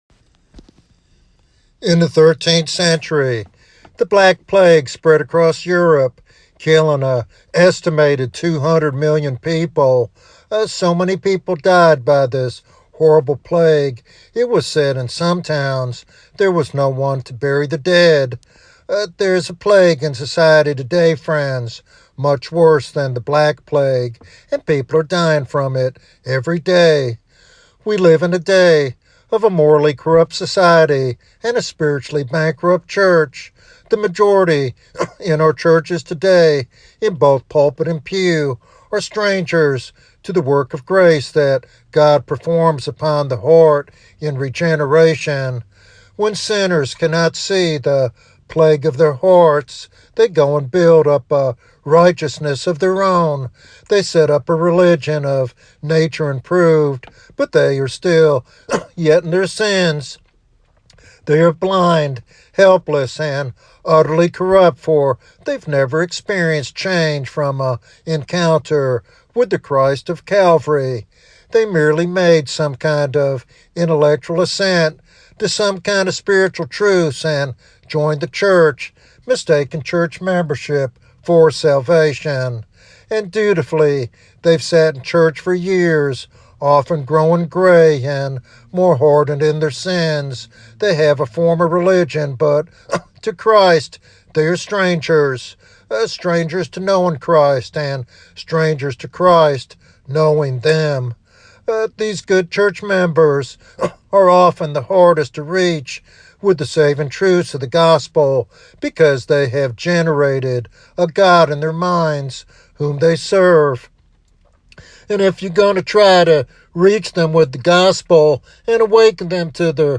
This sermon calls for repentance and a genuine relationship with Jesus, warning of the eternal consequences of neglecting salvation.